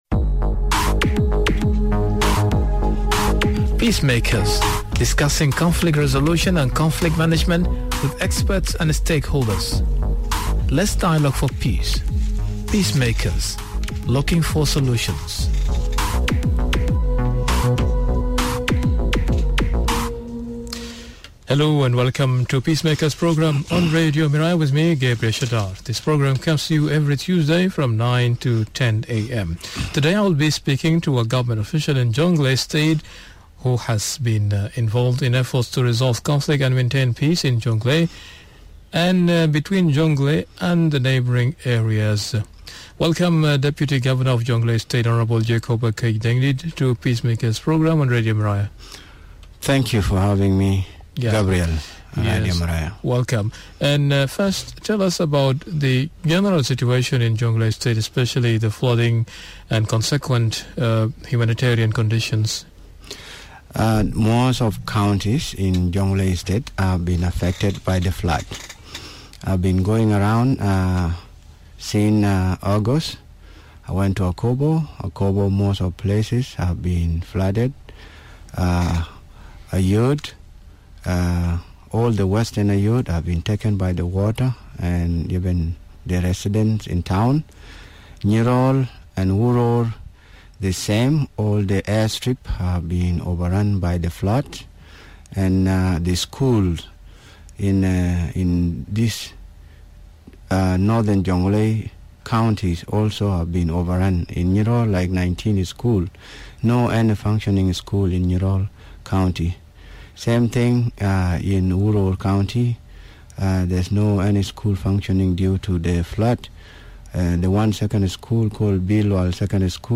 Discussion with Deputy Governor of Jonglei State Hon Jacob Akech Dengdit on his Peacekeeping efforts in Jonglei State.